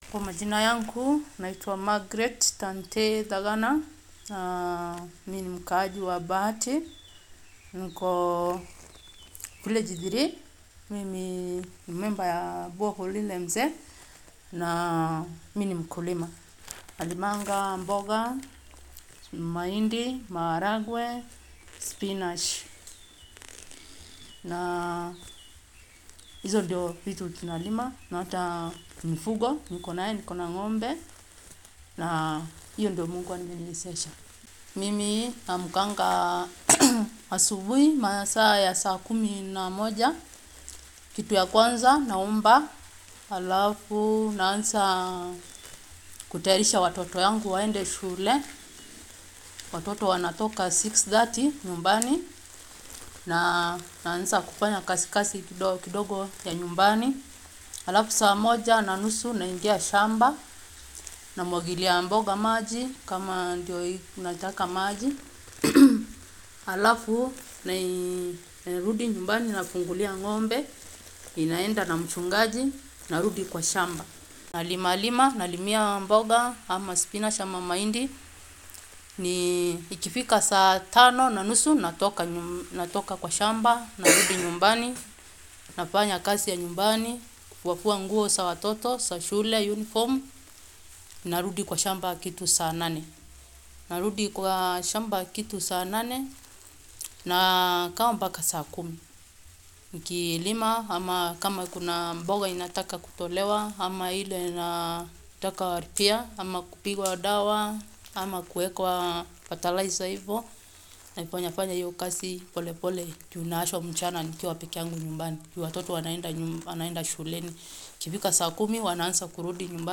Interview als Audio (nicht übersetzt):